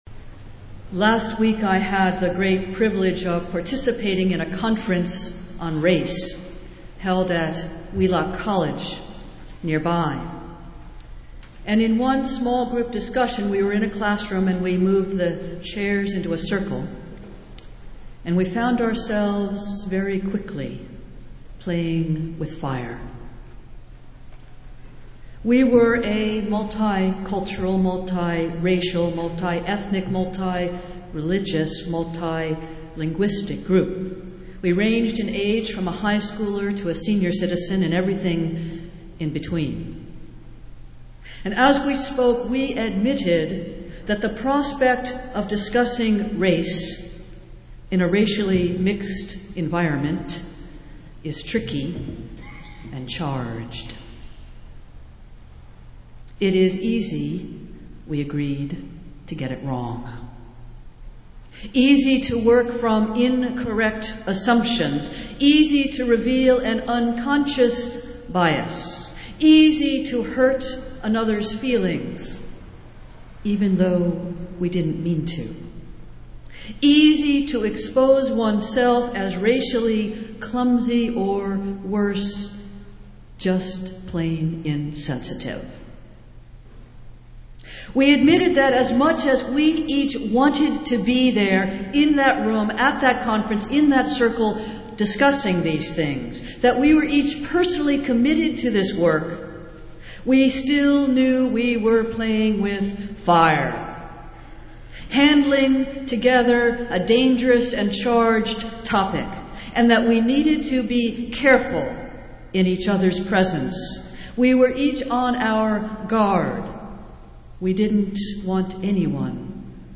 Festival Worship - Pentecost Sunday